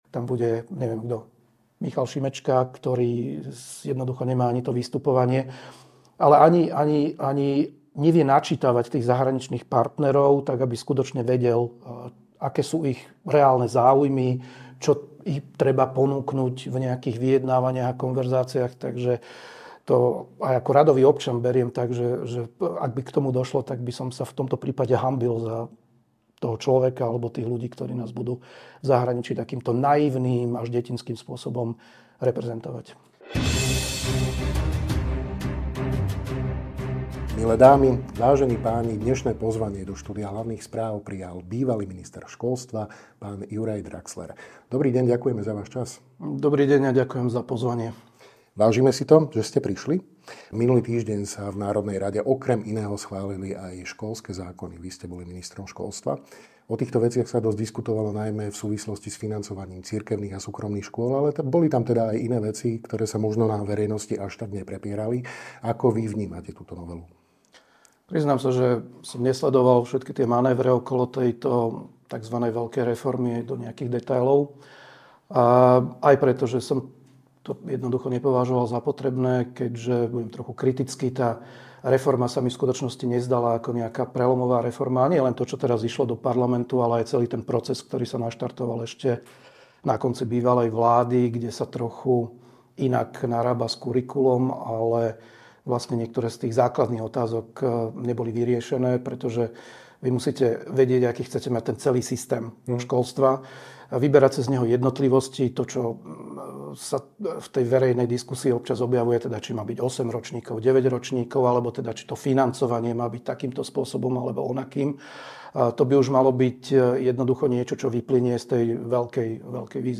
Juraj Draxler v rozhovore pre Hlavné správy otvorene hovorí o tom, prečo považuje aktuálne školské reformy za nedotiahnuté a